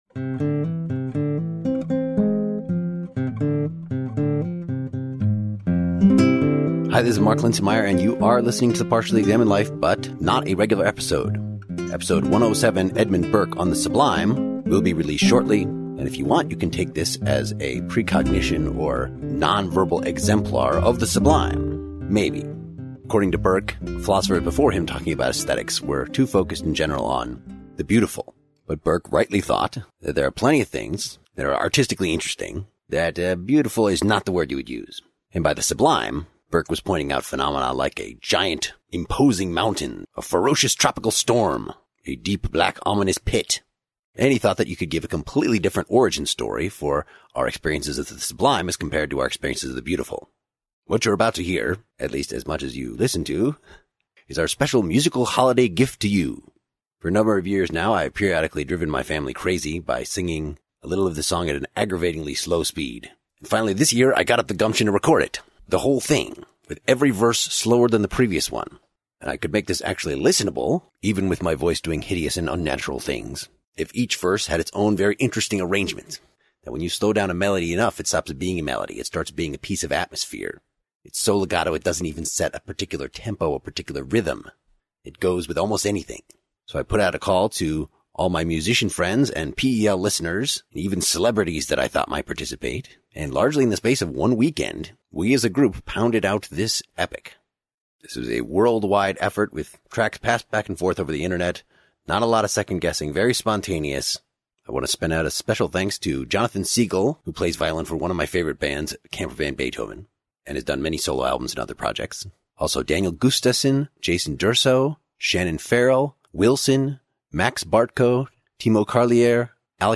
the longest, slowest, biggest, fattest, most surreal Christmas carol ever
with different verses of the holiday classic arranged in styles from baroque to folk to metal to disco.